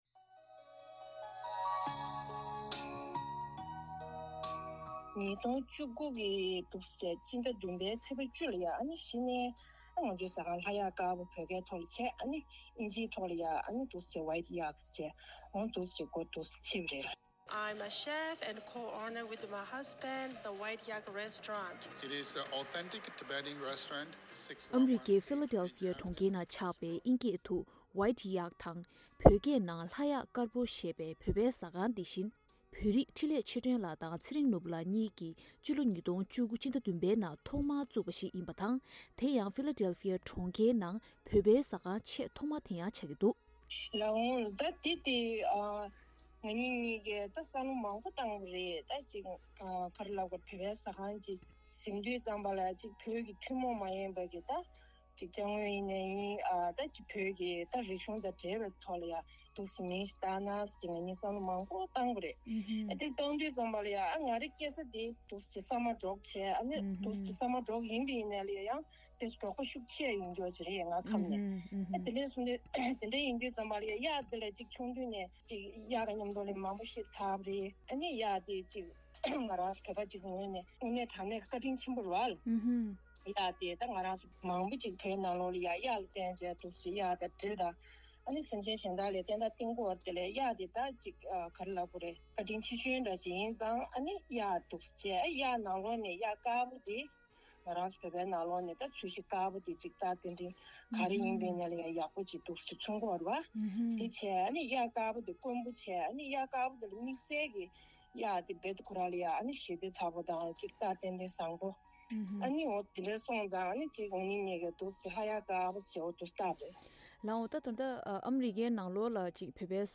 འདྲི་བ་དྲིས་ལན